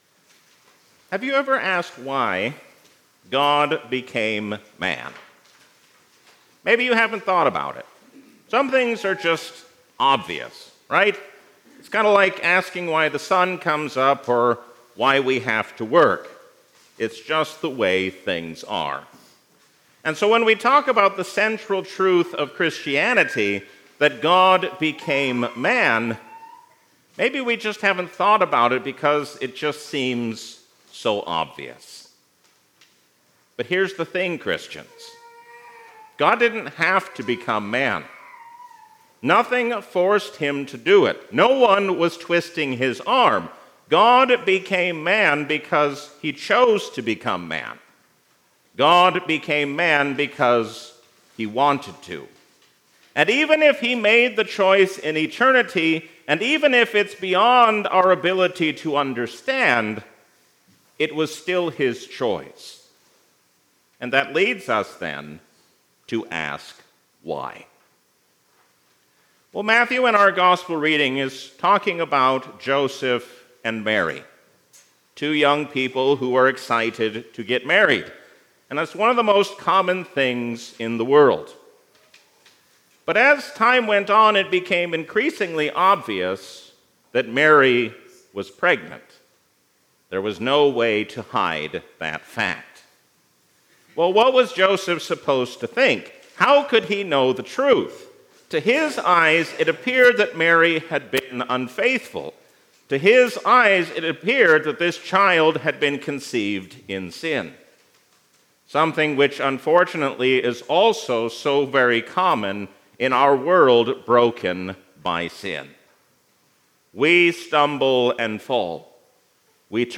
A sermon from the season "Christmas 2025."